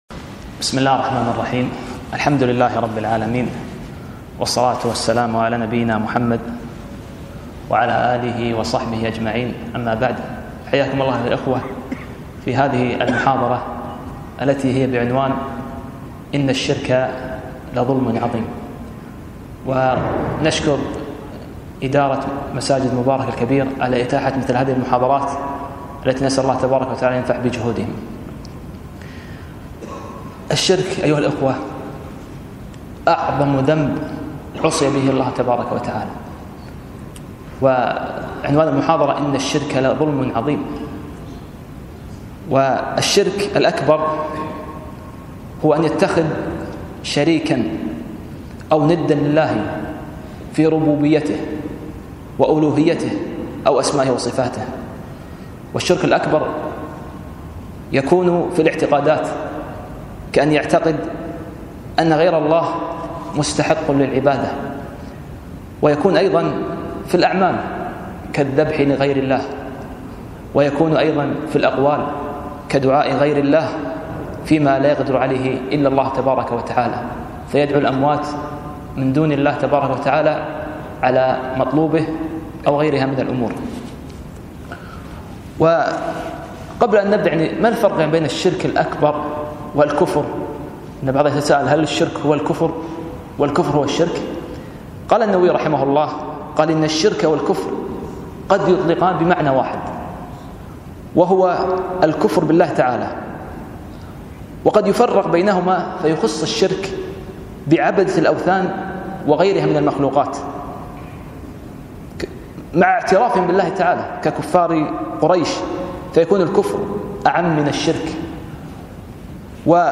كلمة - (إن الشرك لظلم عظیم)